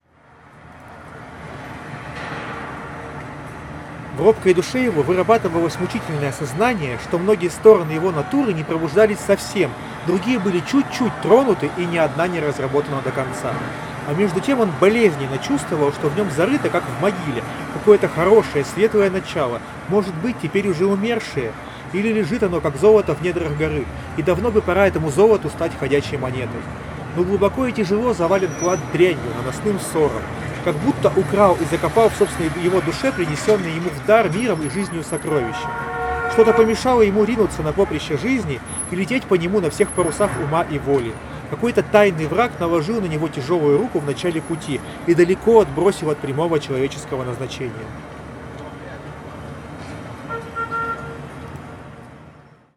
Чтобы оценить ее эффективность, мы вновь запишем два тестовых фрагмента, но на этот раз добавим фоновый шум: включим в расположенной недалеко от места записи акустике звук оживленной улицы и посмотрим, как это отразится на разборчивости речи.
Запись на профессиональный рекордер (в шумной обстановке)
4-Recorder-Noise.mp3